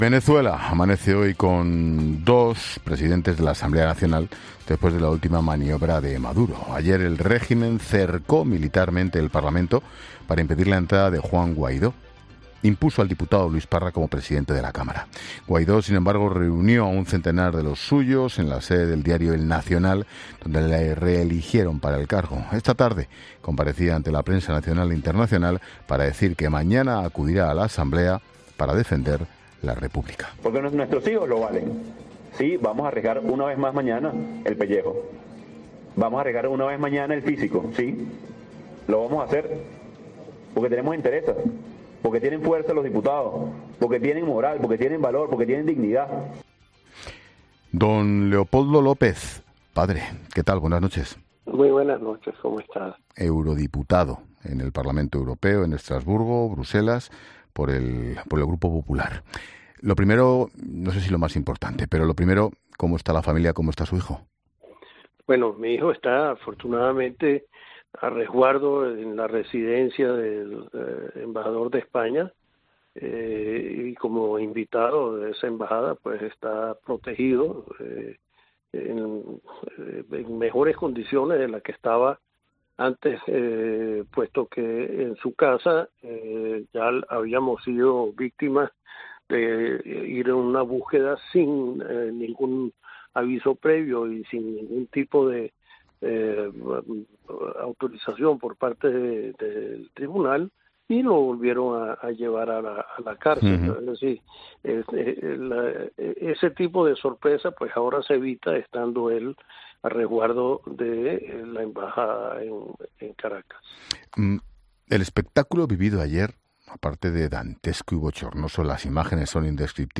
Leopoldo López Gil, padre del disidente Leopoldo López, nos atiende en La Linterna.